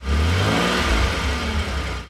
rev_out3.ogg